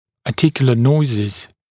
[ɑː’tɪkjulə ‘nɔɪzɪz][а:’тикйулэ ‘нойзиз]суставные шумы; шумы, возникающие в суставе